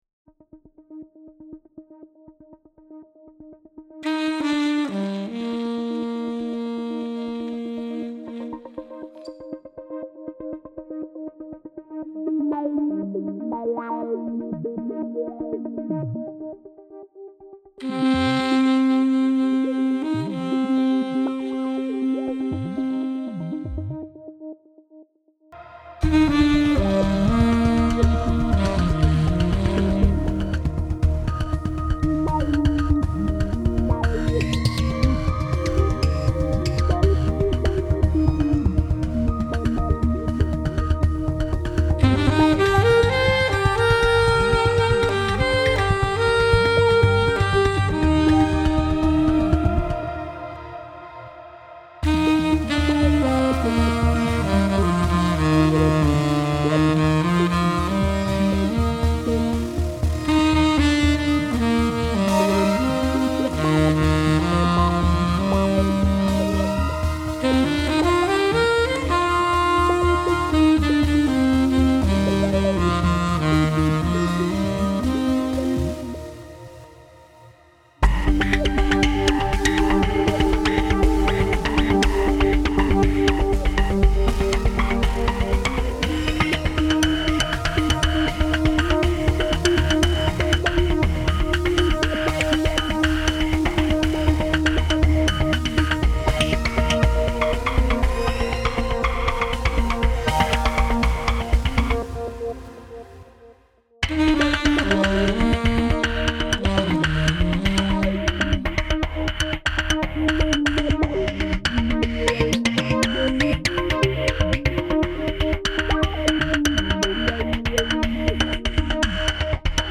Erstmalig setzte ich den neuen Omnisphere 3 ein. Das Altsax ist mein A-WO20, welches teilweise durch einen speziellen Filter fabfilter VOLCANO3 läuft.